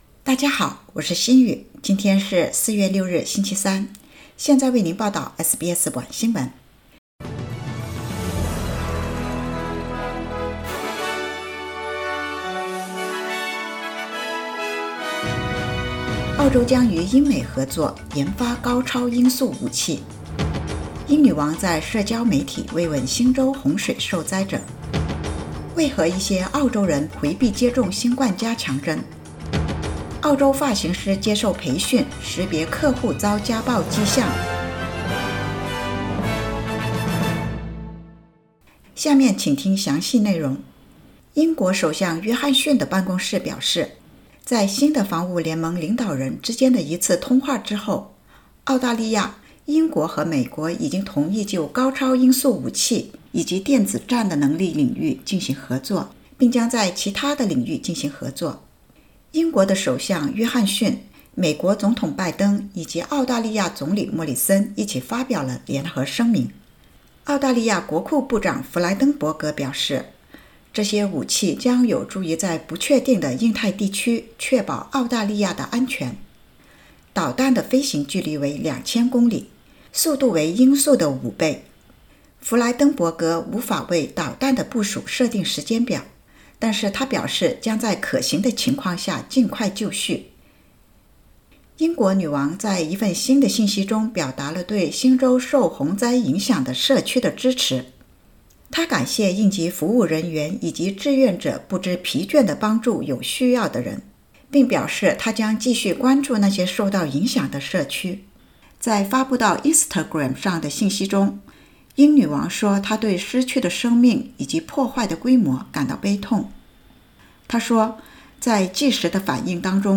SBS晚新聞（2022年4月6日）
SBS Mandarin evening news Source: Getty Images